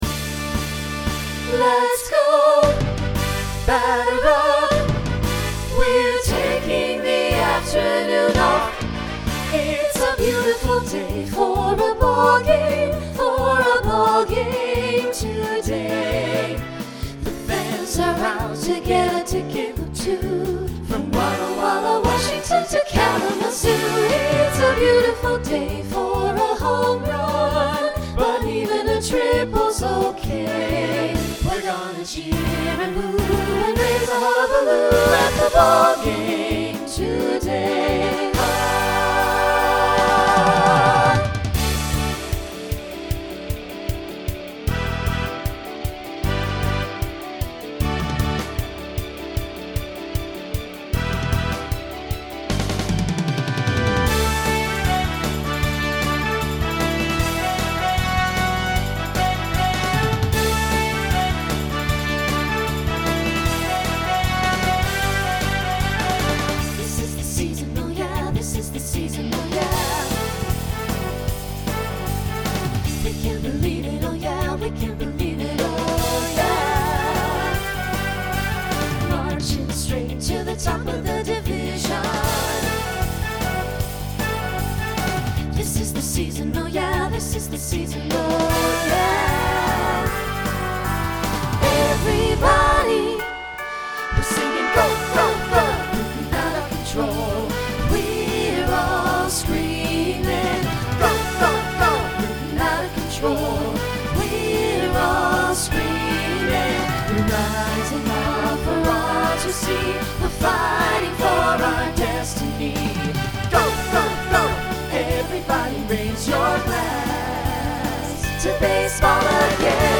Voicing SATB Instrumental combo Genre Broadway/Film , Rock